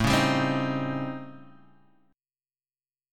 A7#9b5 chord